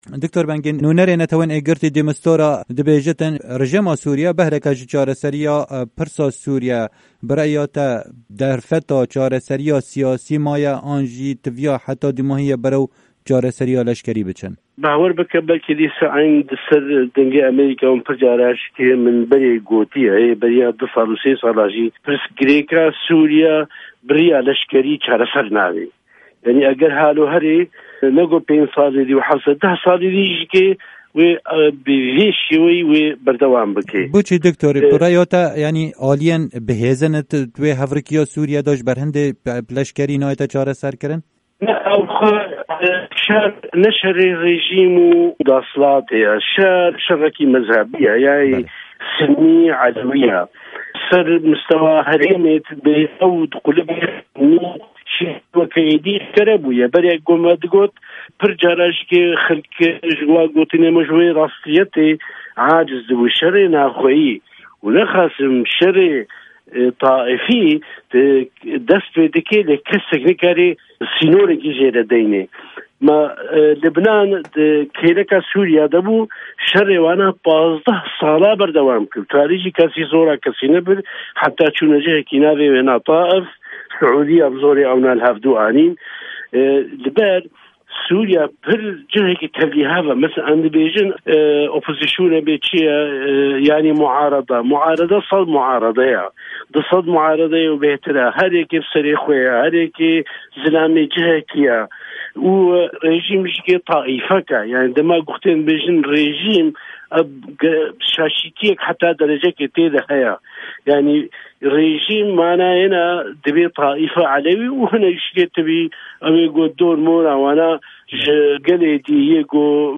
Di hevpeyvînekê de ligel Dengê Amerîka